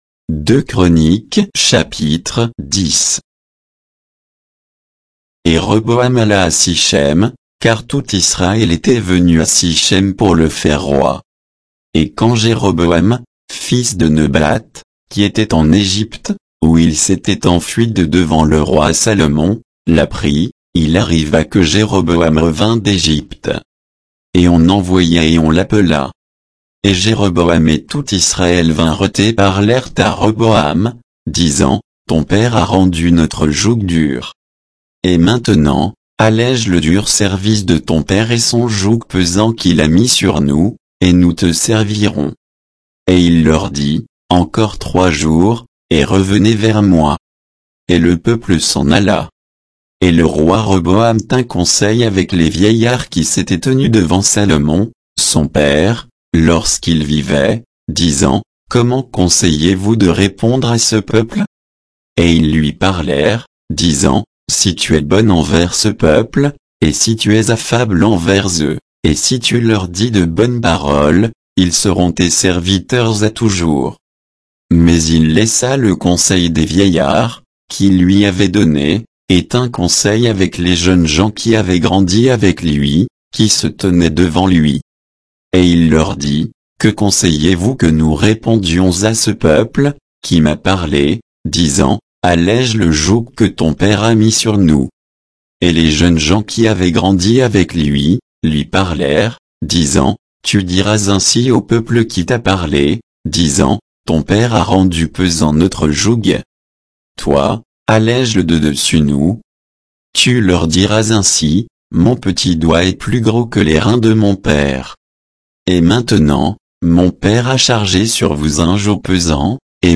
Bible_2_Chroniques_10_(texte_uniquement).mp3